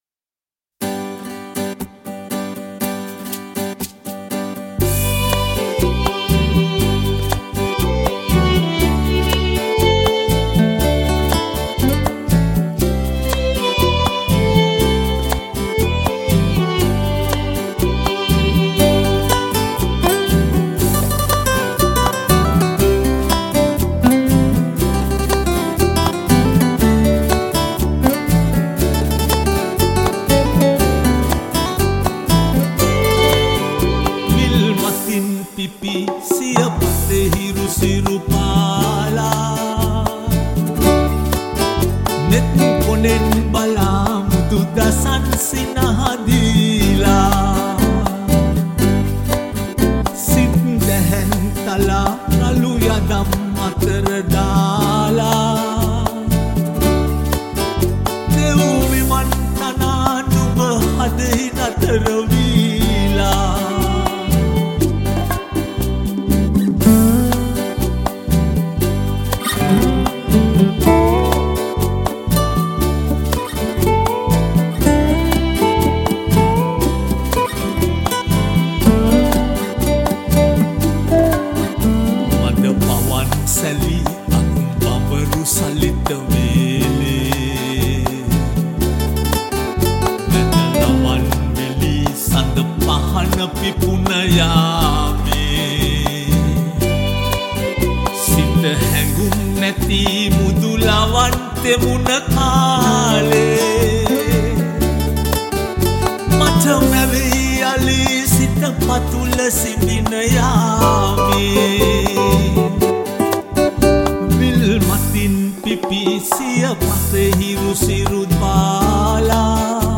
All these songs were recorded (or remastered) in Australia.
Vocals